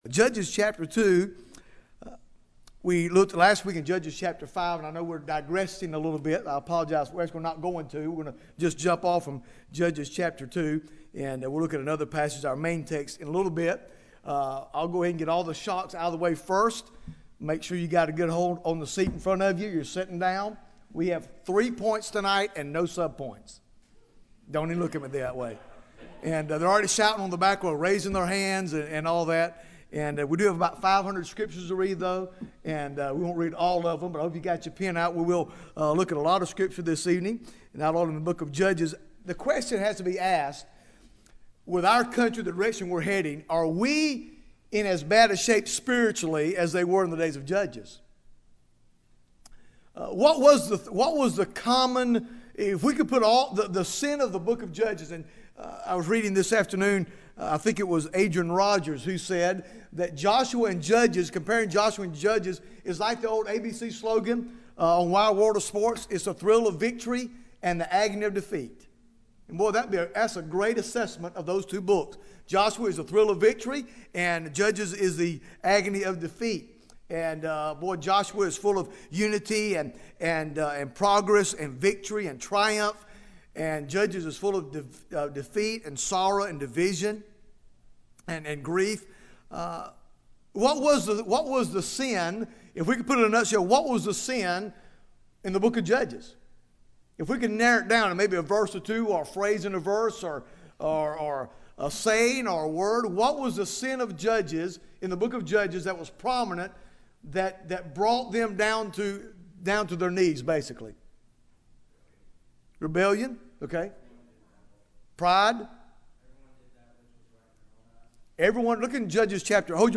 Bible Text: Judges 2 | Preacher